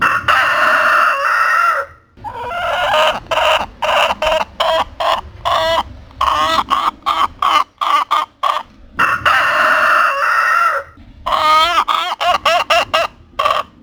Als hij zijn best doet om mooi te kraaien wordt hij door de dames uitgelachen!
haan_en_hen.mp3